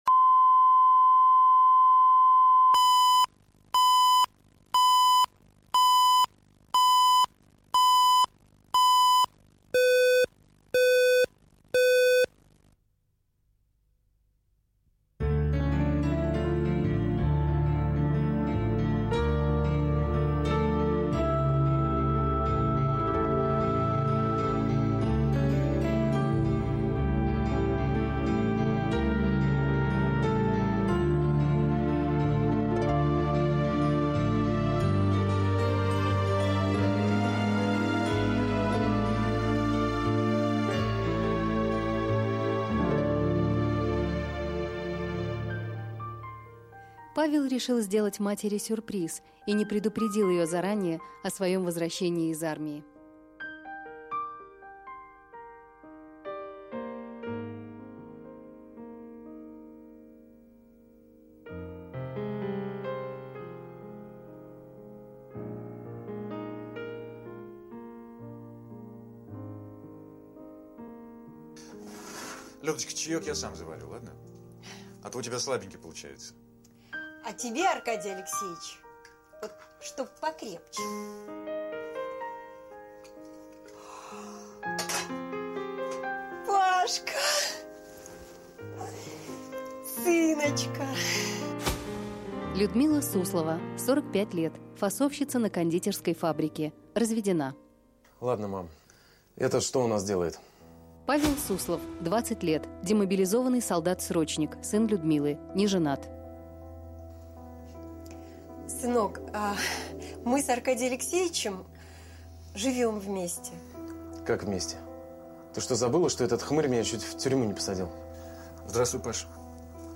Аудиокнига Павлик